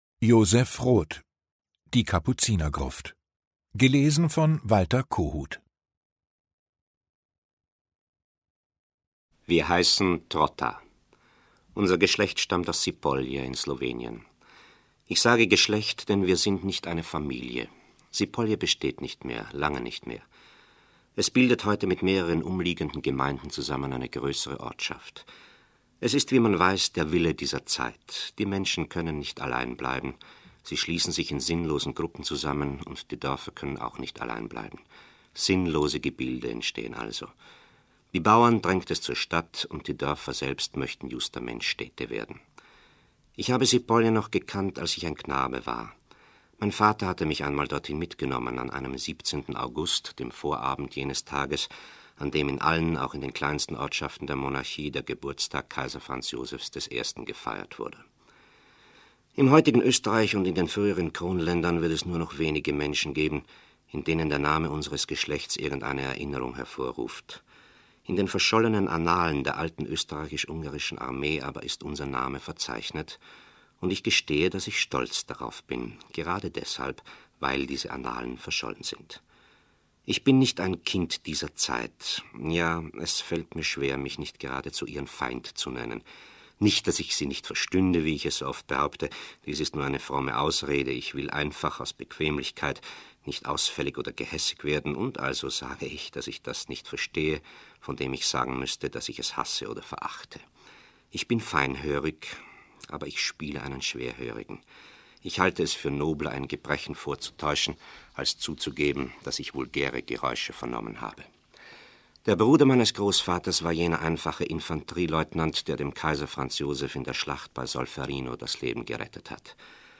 Walter Kohut (Sprecher)
2021 | Gekürzte Lesung
Der österreichische Schauspieler Walter Kohut liest aus dem Leben des Franz Ferdinand Trotta.